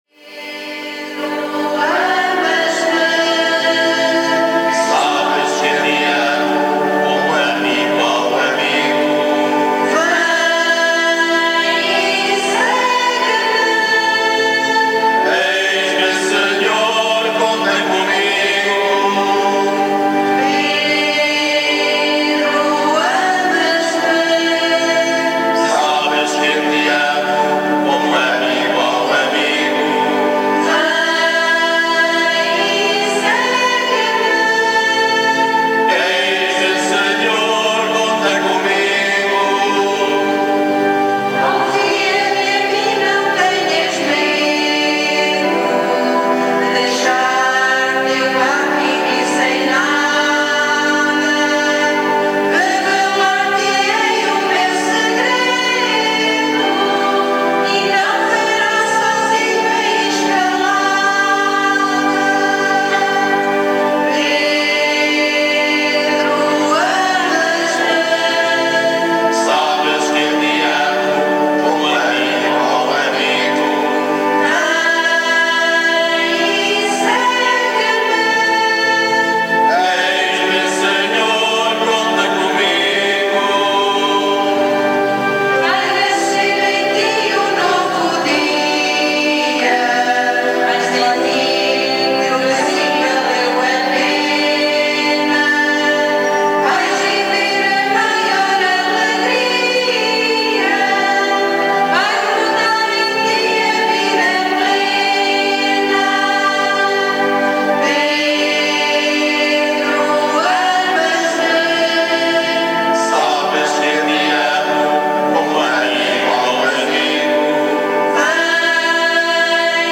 Eucaristia Dominical – 29.Junho.2014
A Rádio Clube de Lamego transmite todos os Domingos a Eucaristia, em direto, desde o Santuário de Nossa Senhora dos Remédios em Lamego a partir das 10 horas.
com a participação do Coro do Santuário de Nossa Senhora dos Remédios.